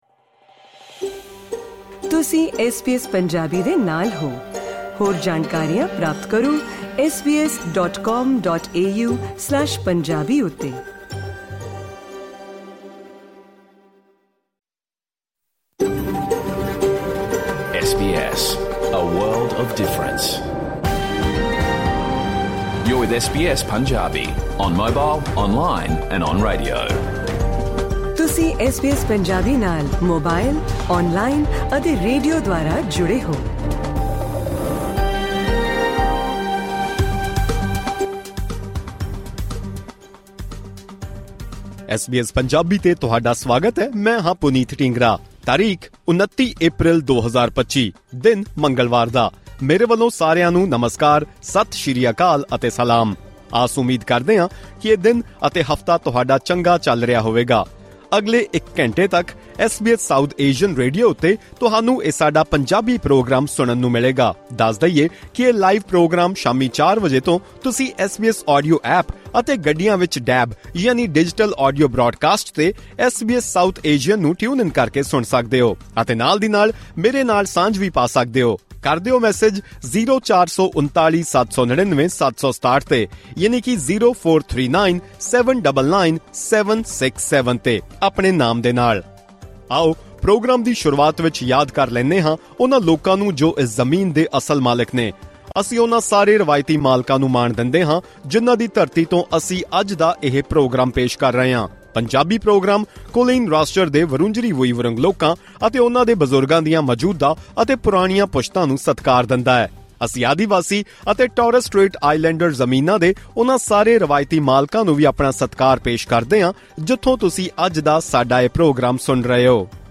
Listen to the SBS Punjabi full radio program